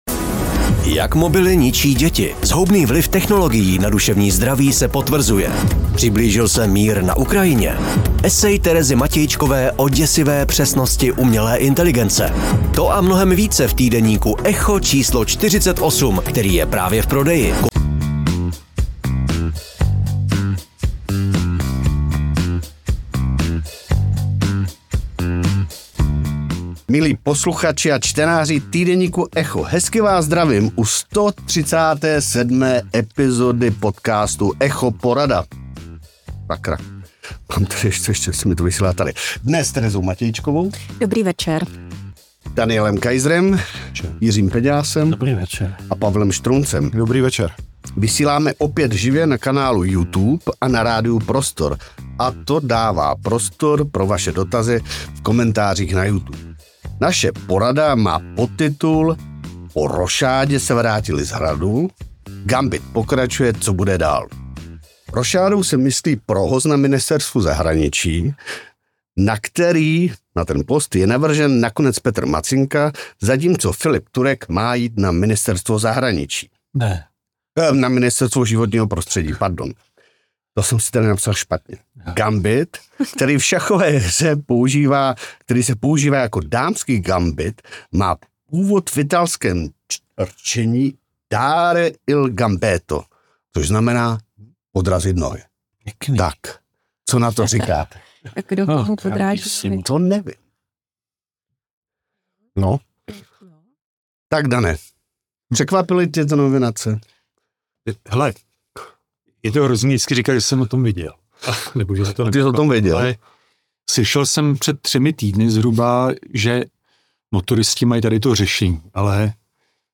Echo Porada se tentokrát vysílala živě.